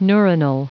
Prononciation du mot neuronal en anglais (fichier audio)
Prononciation du mot : neuronal